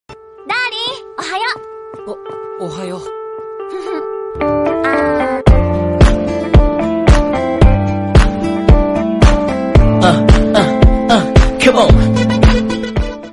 Kategori: Nada dering